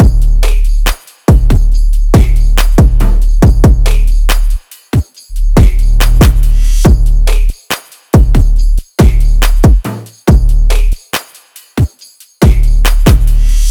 • Full Phonk Drum Sequence - Am 140.wav
Hard punchy drums sample for Memphis Phonk/ Hip Hop and Trap like sound.